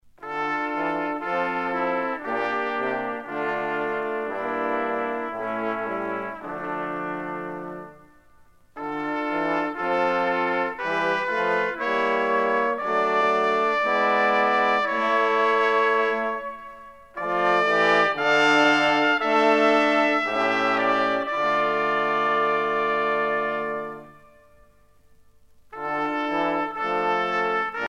Quintette de cuivres
Pièce musicale éditée